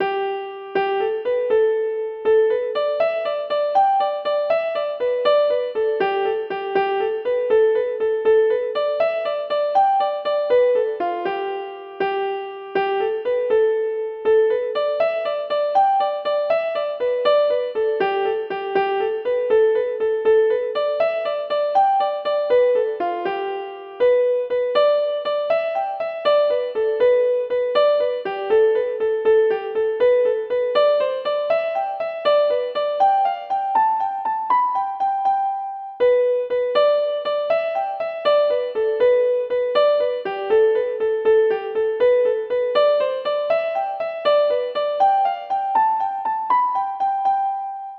Kesh from G to Gmix
In fun...i dunno.  sounds funky.  but probably will depend on each melody and maybe better from tune to tune.
VERY COOL KESH JIG!